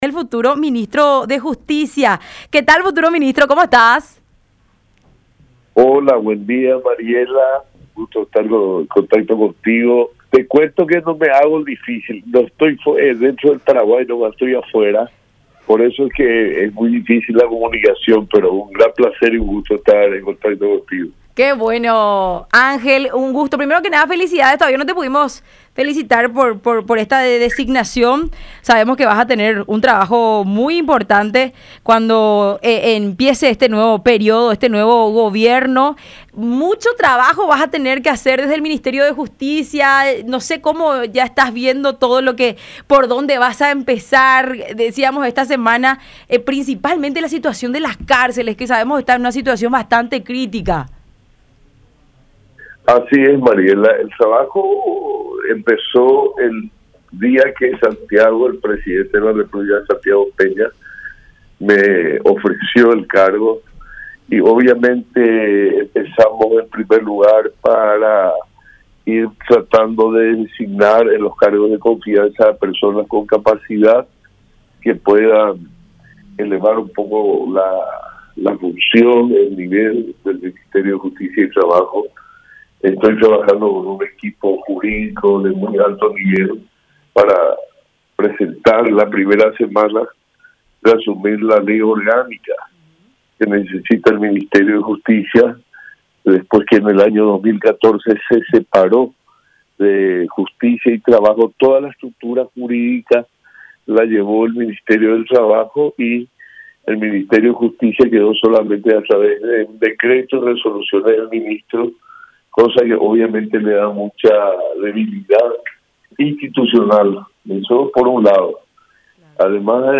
“Vamos a trabajar con mucha fuerza, con la Ley y por el camino de la Ley porque hay muchos casos de corrupción”, dijo Barchini en diálogo con La Unión Hace La Fuerza a través de Unión TV y radio La Unión.